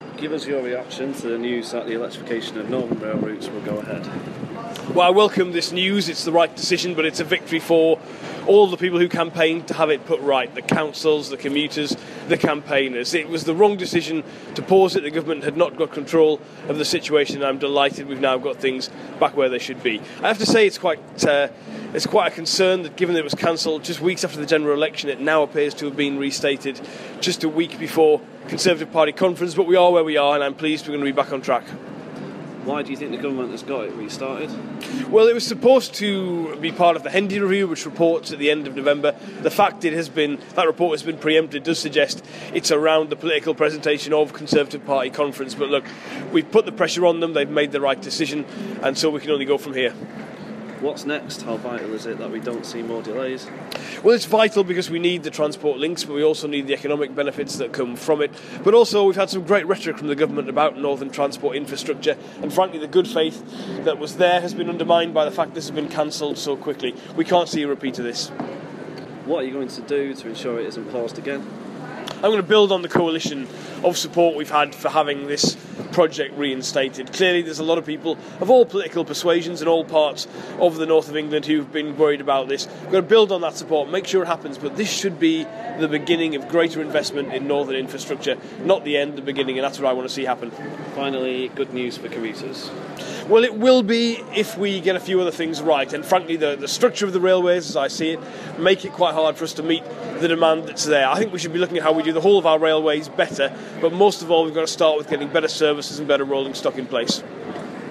Shadow Transport Minister and MP for Hyde and Stalybridge speaks to Key 103 about the restarting of the electrification of northern rail routes.